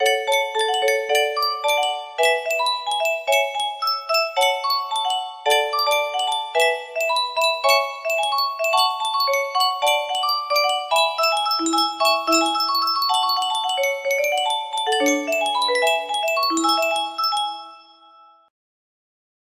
2 music box melody
Grand Illusions 30 (F scale)